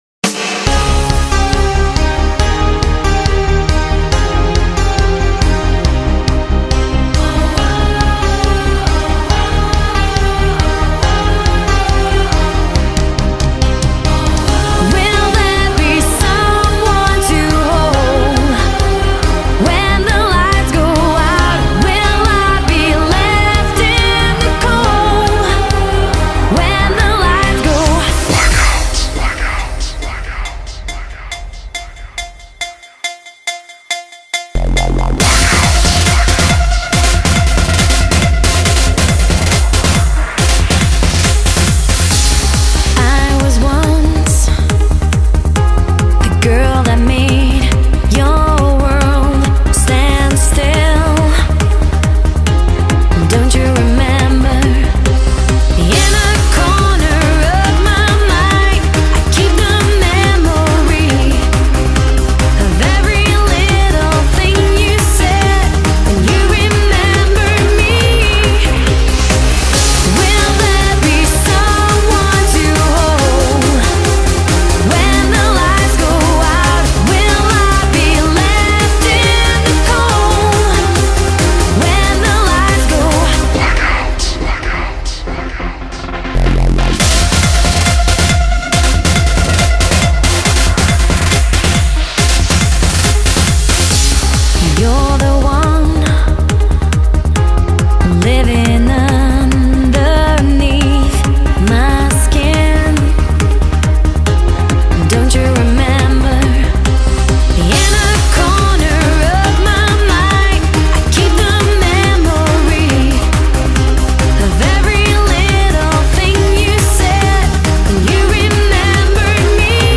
BPM69-139